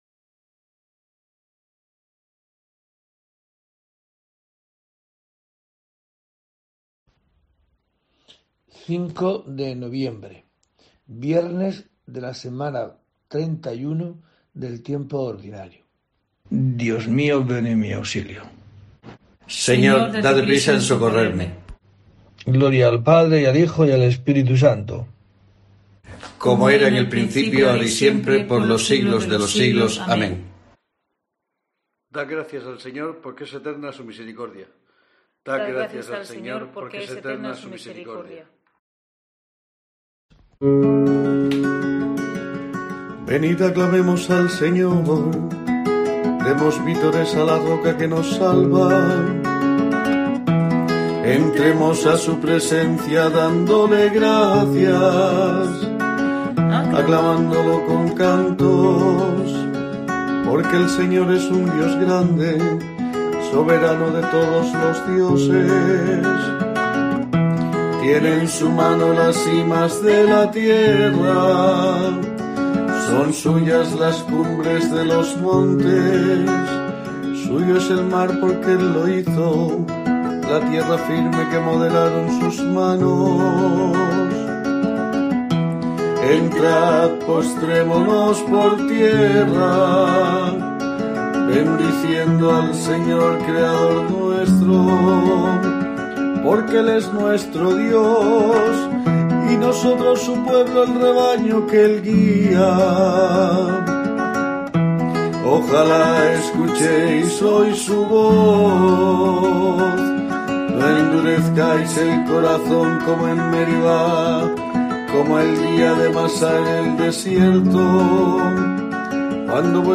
05 de noviembre: COPE te trae el rezo diario de los Laudes para acompañarte